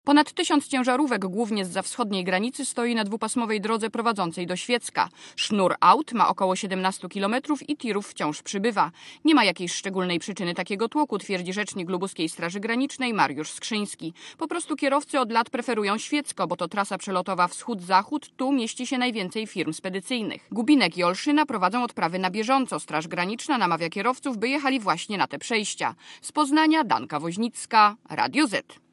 Relacja reportera Radia Zet (225Kb)